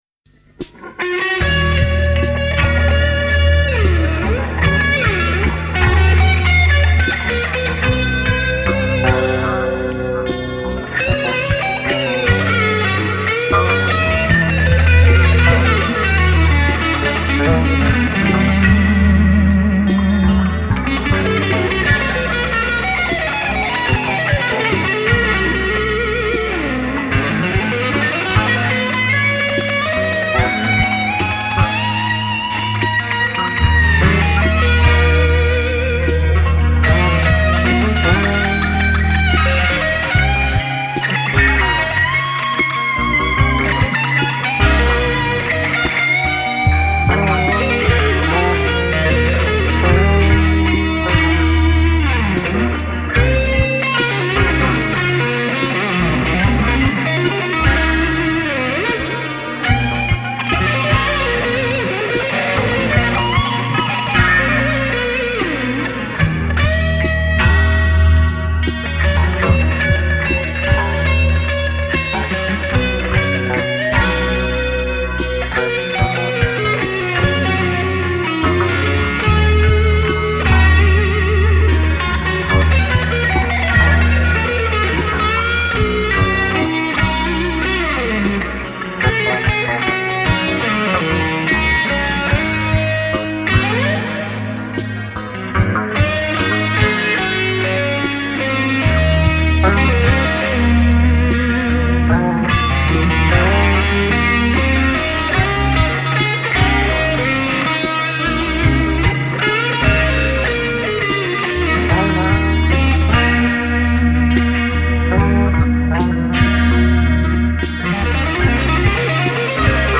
A site offering Indian flute music for relaxation